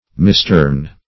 Misturn \Mis*turn"\, v. t. To turn amiss; to pervert.